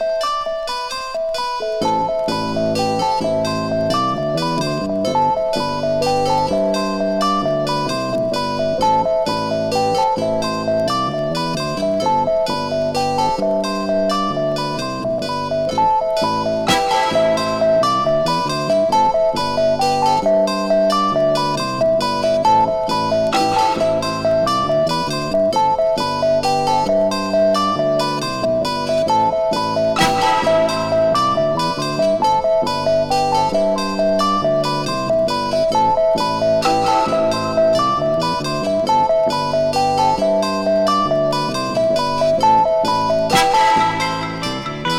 盤自体に起因するプチプチ音有り